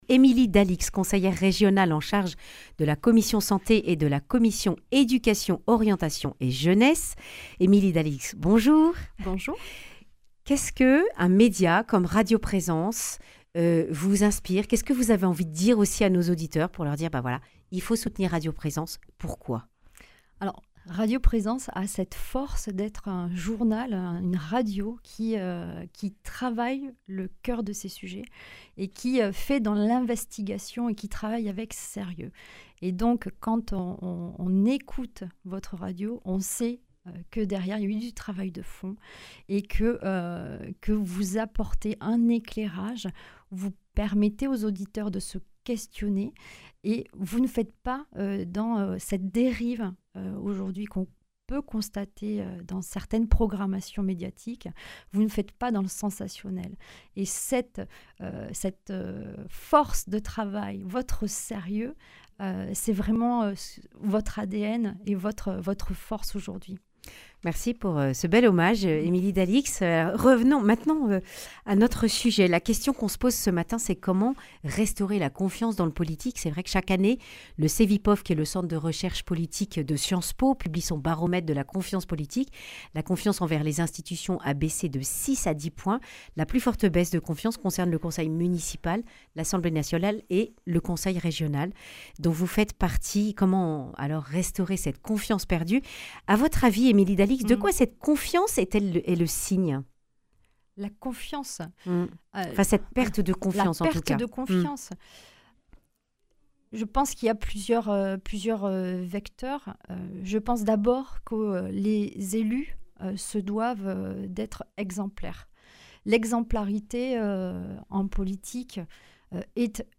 vendredi 1er décembre 2023 Le grand entretien Durée 10 min
Émilie Dalix, conseillère régionale d’Occitanie, membre des commissions Santé, et Éducation, Orientation et Jeunesse, réagit au dernier baromètre du CEVIPOF montrant la baisse de confiance des citoyens envers le politique.